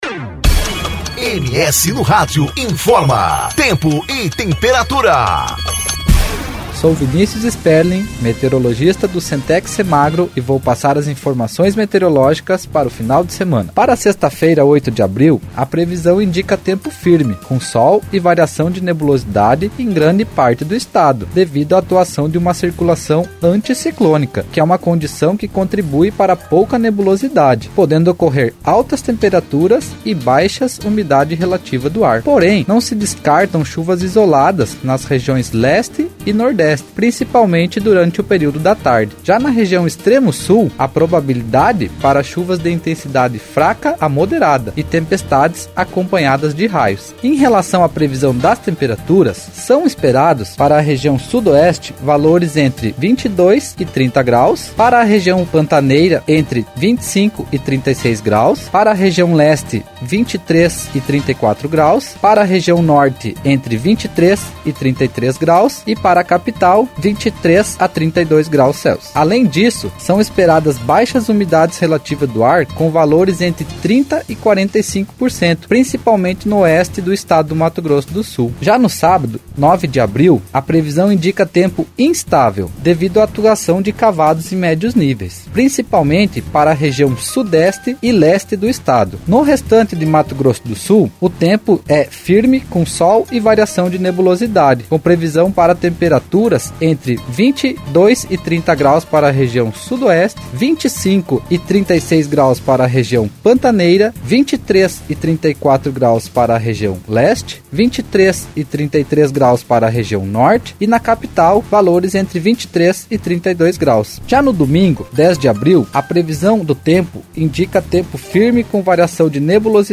Previsão do Tempo: Final de semana será marcado por altas temperaturas e baixa umidade relativa do ar